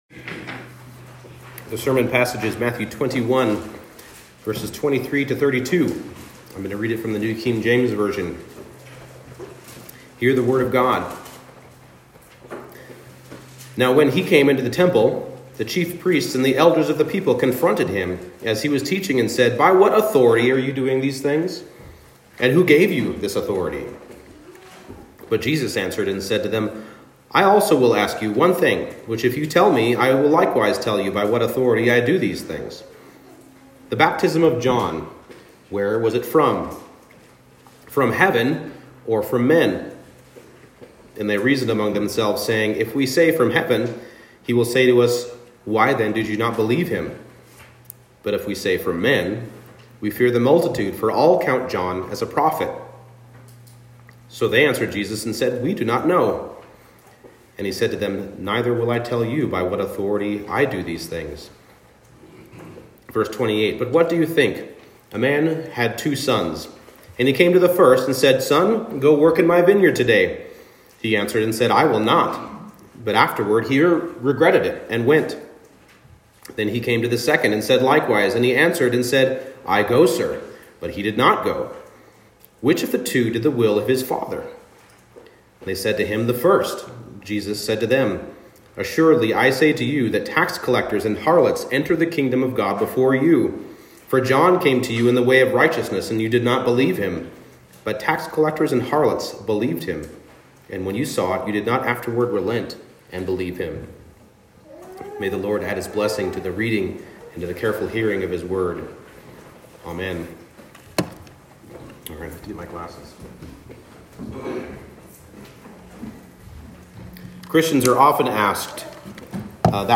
Matthew 21:23-32 Service Type: Morning Service The evidence of Christ’s rule is ample